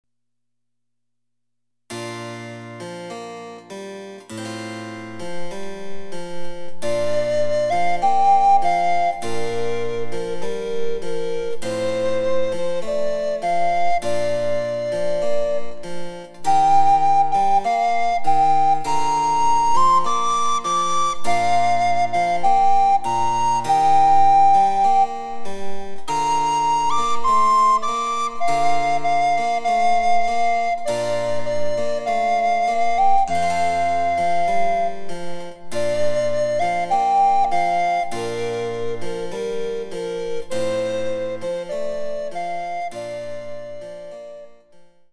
チェンバロ伴奏で楽しむ日本のオールディーズ、第５弾！
※伴奏はモダンピッチのみ。